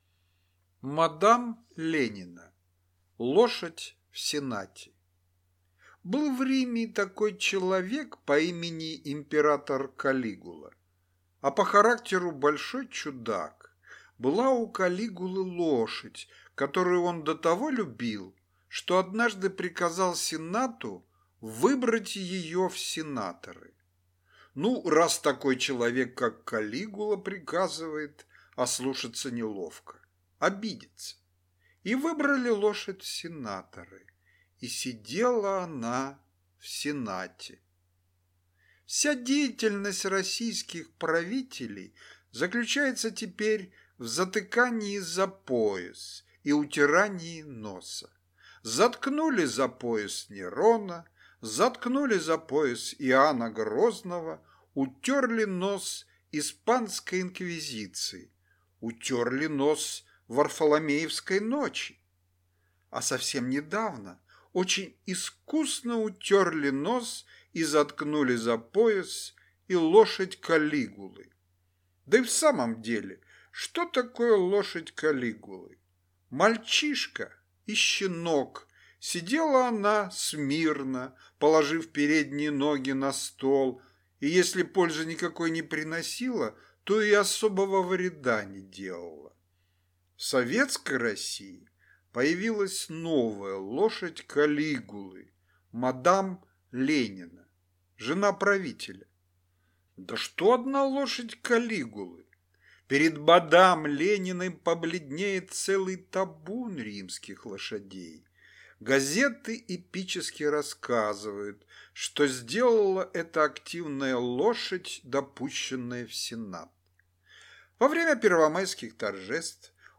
Аудиокнига Двенадцать портретов (в формате «будуар») | Библиотека аудиокниг